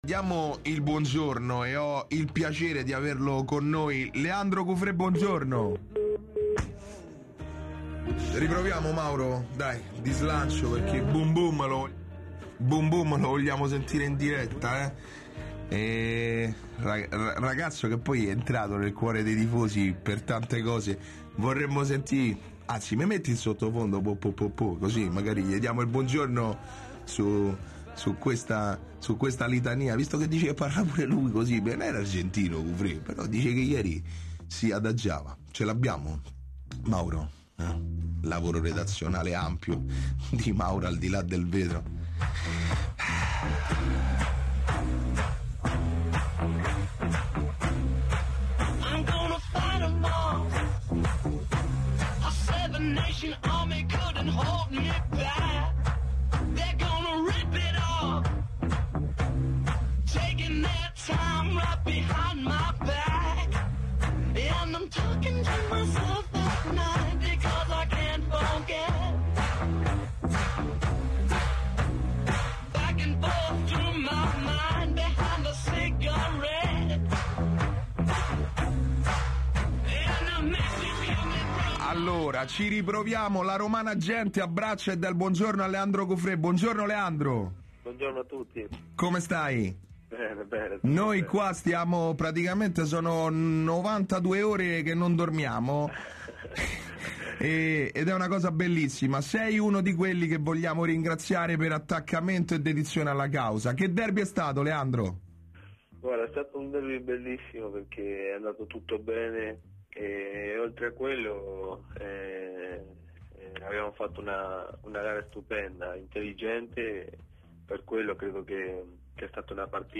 Intervista a Leandro Cufrè
0506lazioroma_intervistacufre.mp3